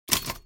دانلود آهنگ تصادف 24 از افکت صوتی حمل و نقل
دانلود صدای تصادف 24 از ساعد نیوز با لینک مستقیم و کیفیت بالا
جلوه های صوتی